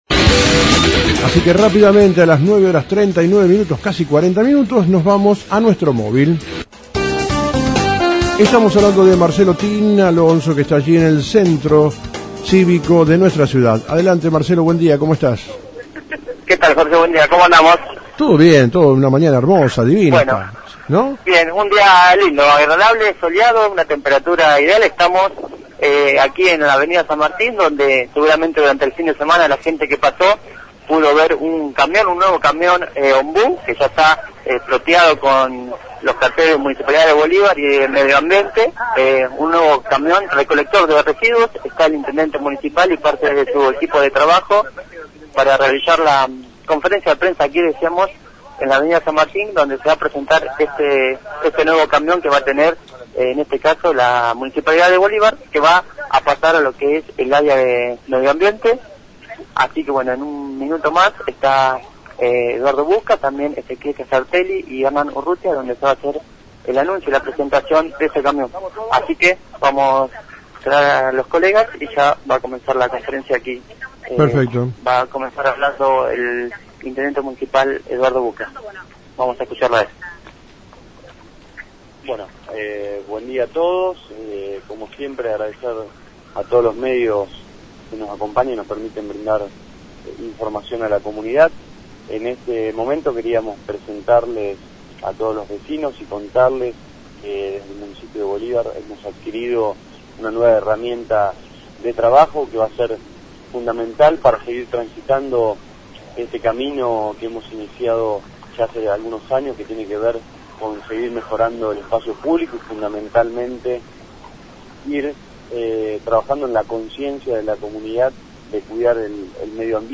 Conferencia de Prensa del Intendente Bucca y El Director de Medio Ambiente Ezequiel Casartelli